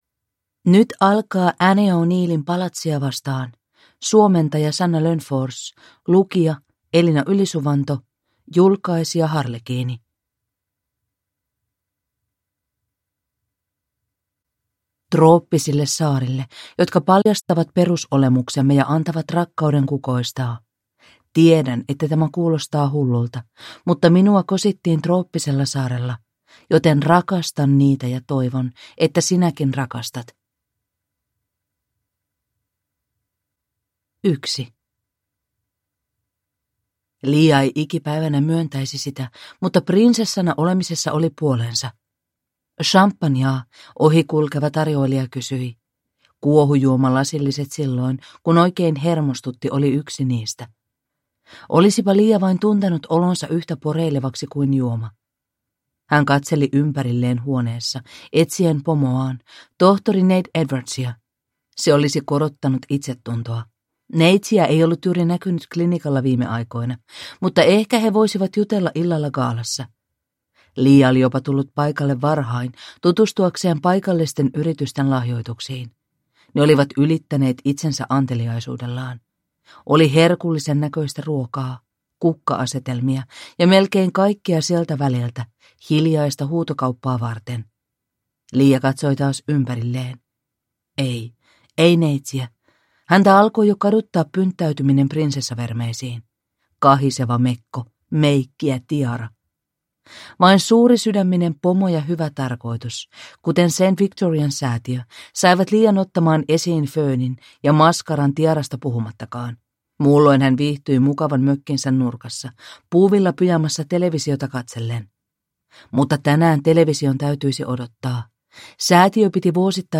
Palatsia vastaan (ljudbok) av Annie O'Neil | Bokon